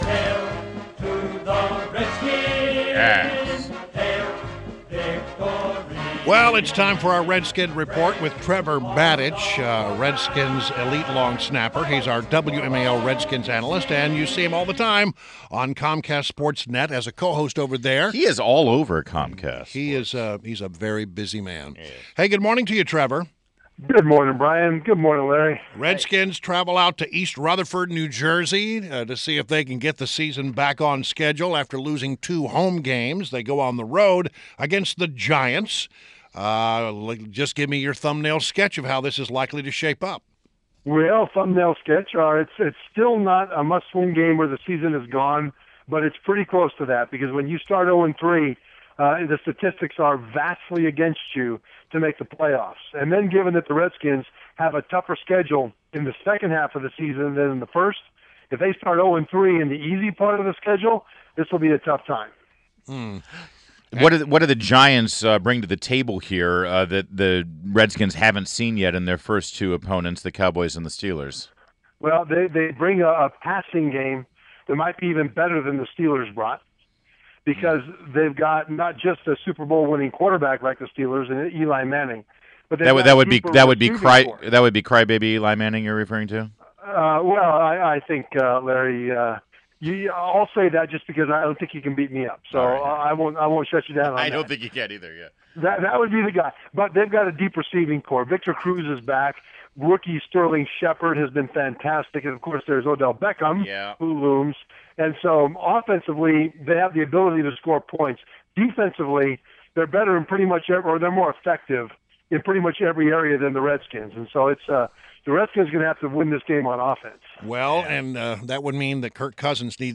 INTERVIEW — TREVOR MATICH — Redskins elite long snapper, WMAL’s Redskins analyst and Comcast SportsNet co-host